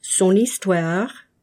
When one word ends in an “n” or “s” and is followed by a word that starts with a vowel sound, you must pronounce the “n” as itself and the “s” as a “z”.
Click on each of the following examples of liaison, and repeat the proper pronunciation after the speaker.
son_histoire.mp3